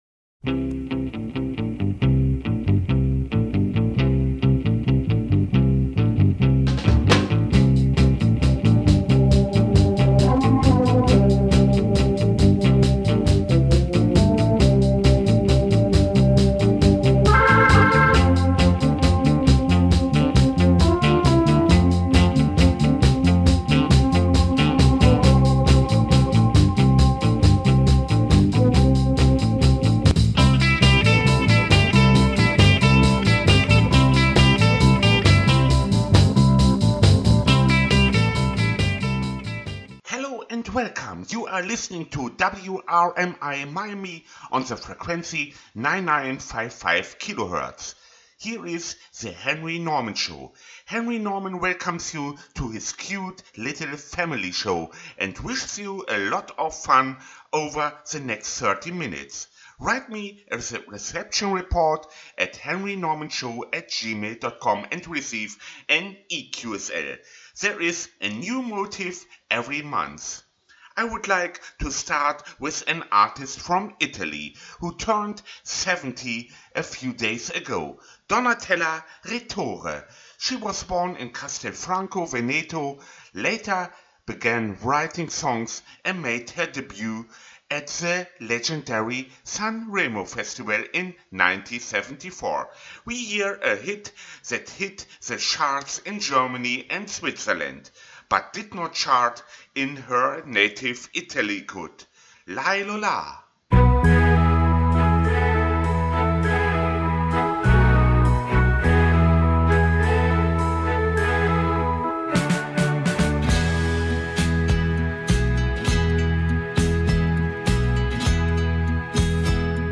This month with Oldies and new Musicsongs